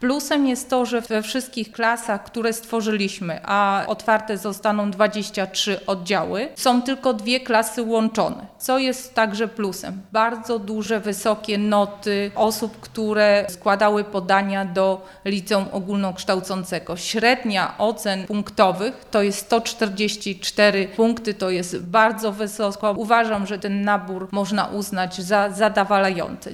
– Uważam, że nabór jest zadowalający – powiedziała Sylwia Wojtasik, członek Zarządu Powiatu Nowosolskiego: